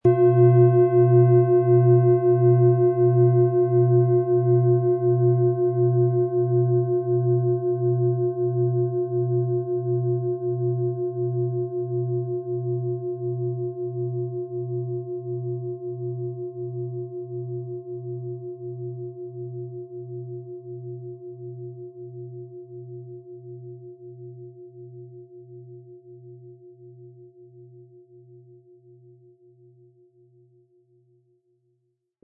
Handgearbeitete Lilith Klangschale.
• Mittlerer Ton: Wasser
PlanetentöneLilith & Wasser
MaterialBronze